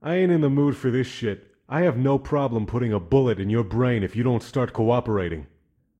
EscortPissedOff1.ogg